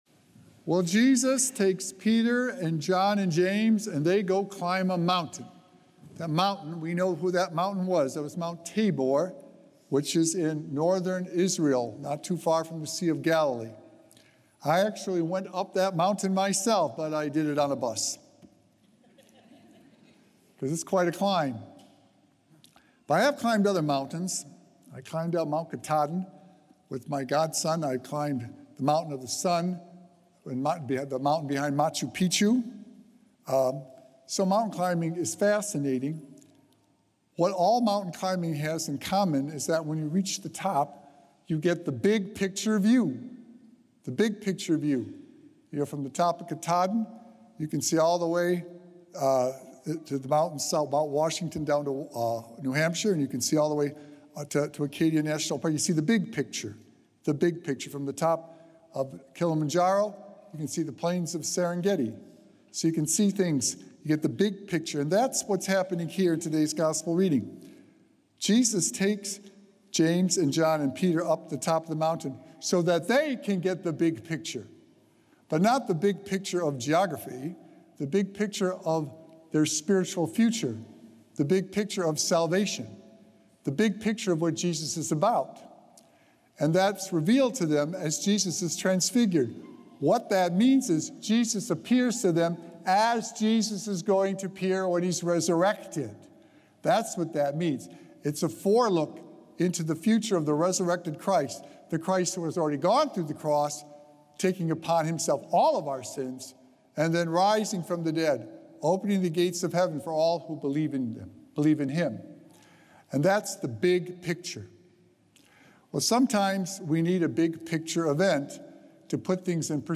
Sacred Echoes - Weekly Homilies Revealed
Recorded Live on Sunday, March 16th, 2025 at St. Malachy Catholic Church.